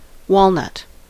Ääntäminen
Synonyymit walnut tree Ääntäminen US : IPA : [ˈwɑl.nət] UK : IPA : /ˈwɔːlnʌt/ Tuntematon aksentti: IPA : /ˈwɔːl.ˌnʌt/ Haettu sana löytyi näillä lähdekielillä: englanti Käännös Substantiivit 1. juglandujo 2. juglandarbo 3. juglando Määritelmät Substantiivit A hardwood tree of the genus Juglans.